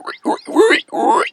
pig_2_hog_seq_09.wav